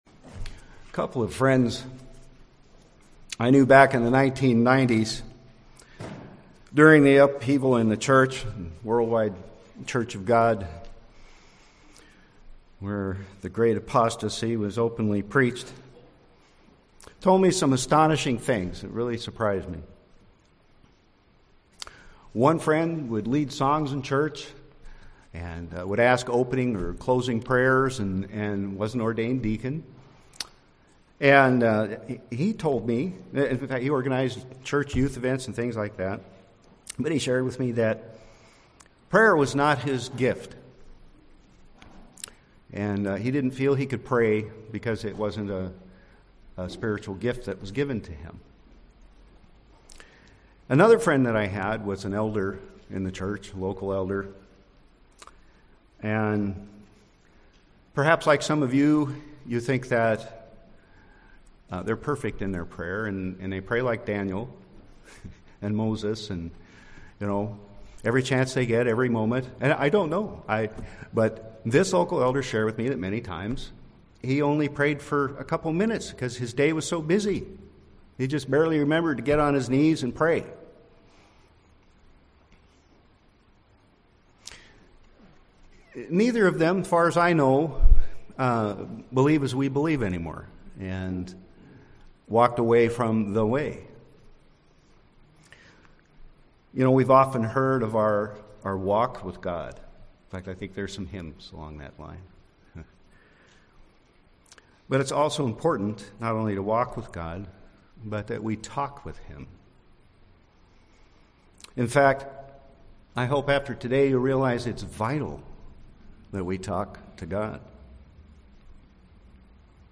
Listen to this sermon and learn how what is sometimes referred to as the "Lord's Prayer" is actually a guide or outline that Jesus provides to us to teach us how to pray.